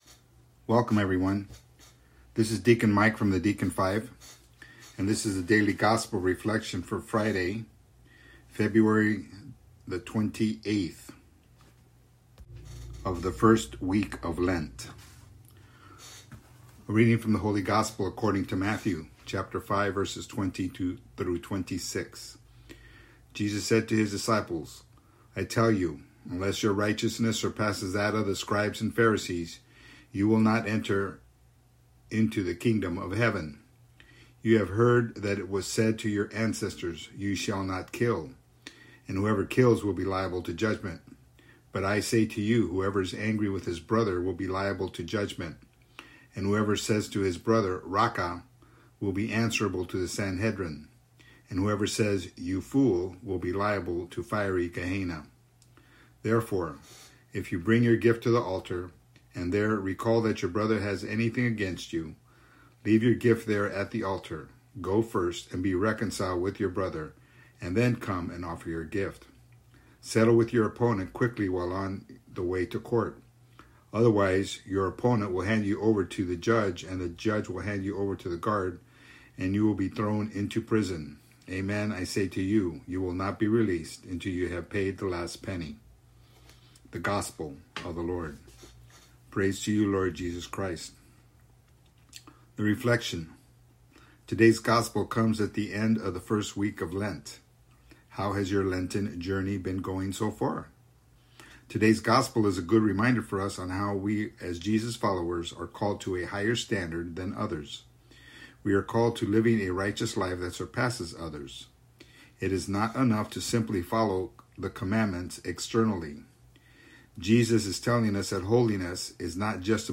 Audio Reflection: